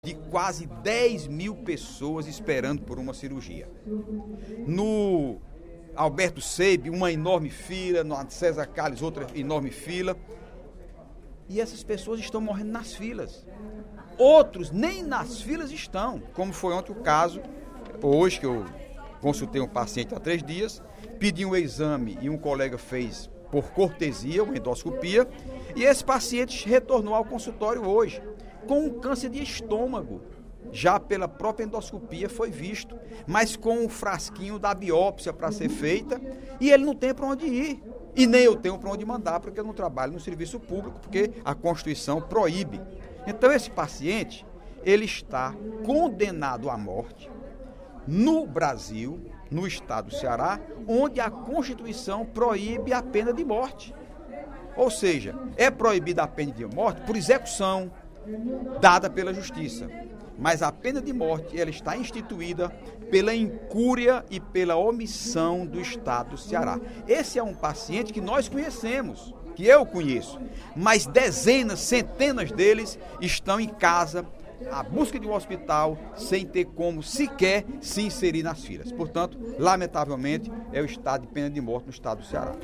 O deputado Heitor Férrer (PSB) disse, durante o primeiro expediente da sessão plenária desta sexta-feira (17/03), que as pessoas que buscam um tratamento no sistema público de saúde estão sendo condenadas à morte, porque não são atendidas.